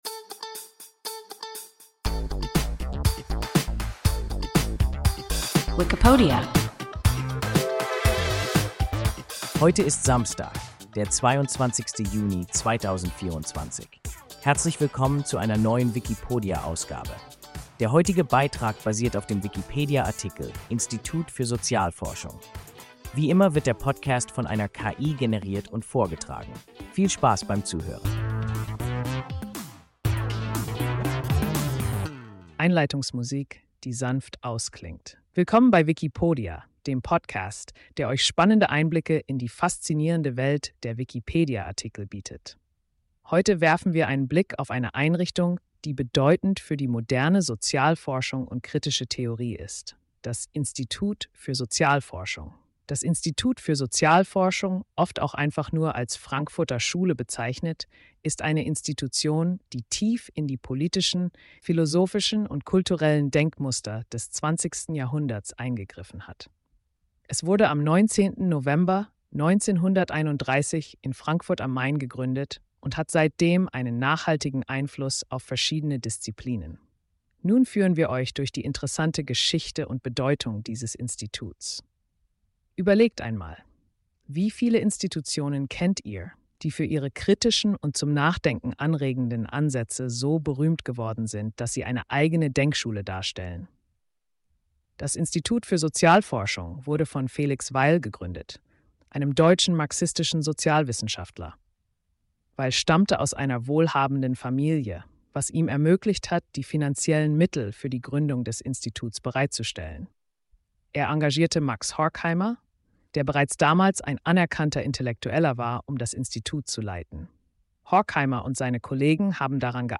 Institut für Sozialforschung – WIKIPODIA – ein KI Podcast